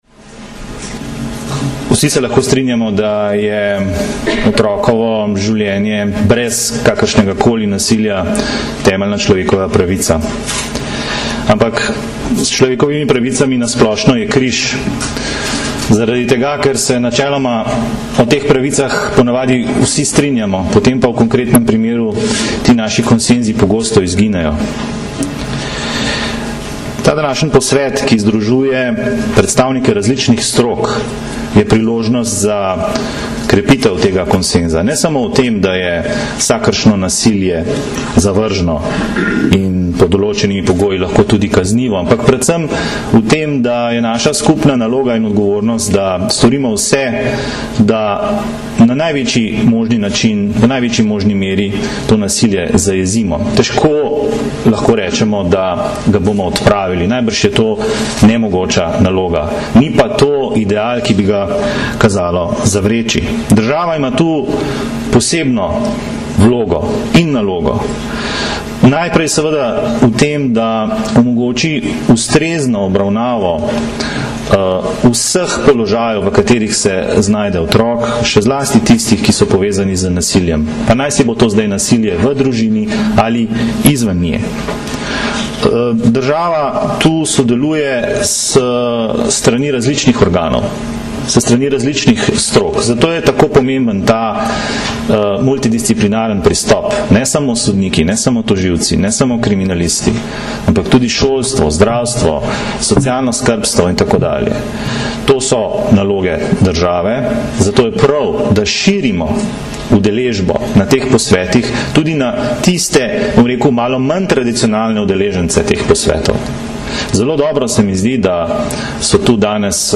Danes, 9. aprila 2010, se je zaključil dvodnevni posvet z naslovom Nasilje nad otroki - razumeti in prepoznati, ki sta ga na Brdu pri Kranju organizirala Generalna policijska uprava in Društvo državnih tožilcev Slovenije.
Zvočni posnetek govora ministra za pravosodje Aleša Zalarja (mp3)